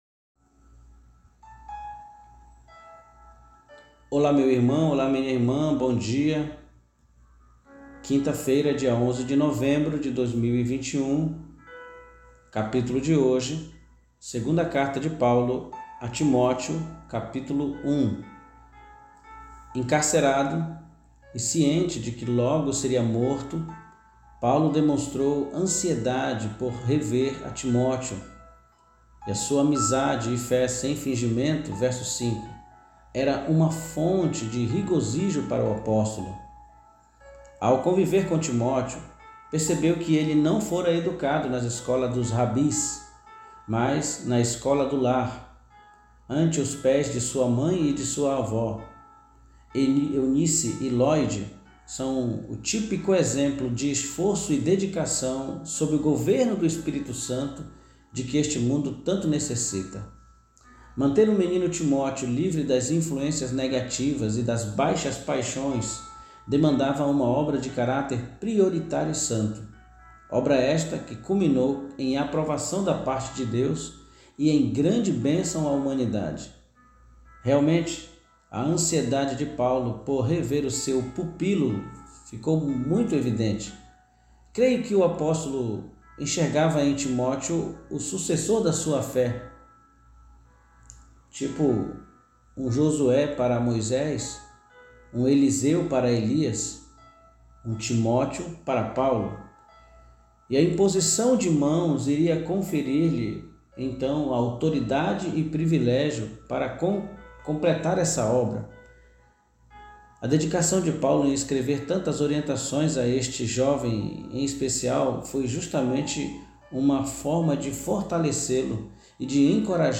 PODCAST DE MEDITAÇÃO BÍBLICA